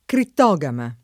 vai all'elenco alfabetico delle voci ingrandisci il carattere 100% rimpicciolisci il carattere stampa invia tramite posta elettronica codividi su Facebook crittogama [ kritt 0g ama ] (raro criptogama ) agg. e s. f. (bot.)